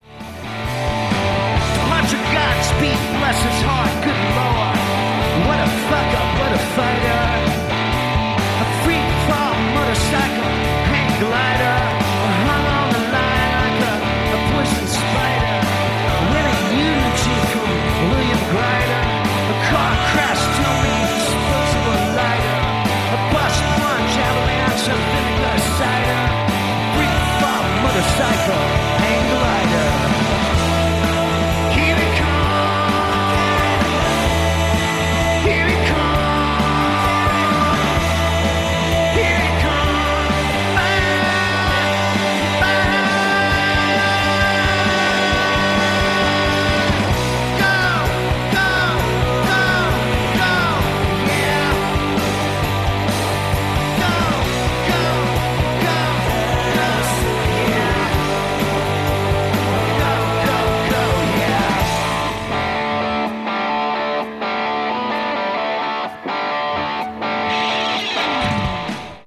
you can just barely make out the audience at the very end.
for their electric live sound